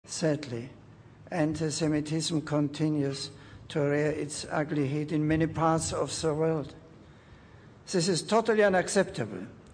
dit een, om zijn uitspraak, vol-strekt hi-la-risch geluidsfragment vindt, uit de aankomstspeech van Paus Benedictus XVI op Ben Gurion Airport in Tel Aviv op 11 mei j.l.?